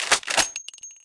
Media:RA_Shelly_Evo.wav UI音效 RA 在角色详情页面点击初级、经典和高手形态选项卡触发的音效